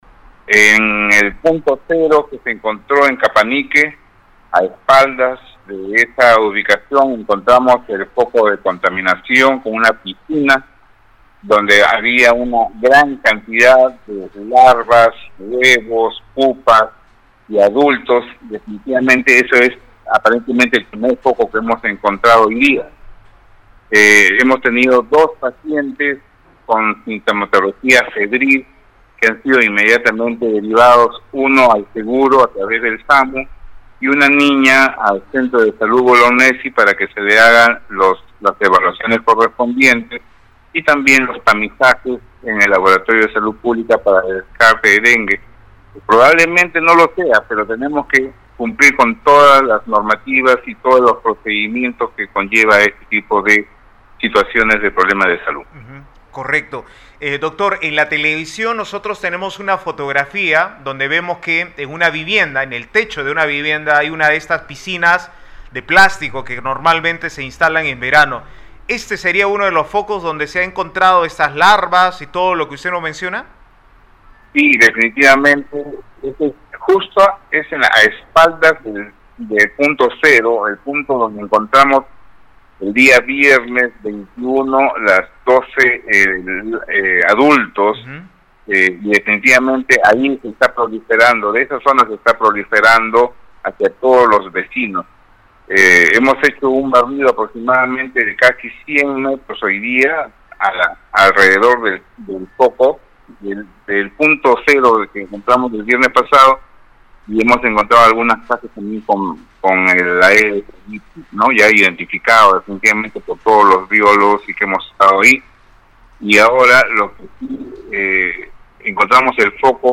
director-de-la-diresa-tacna.mp3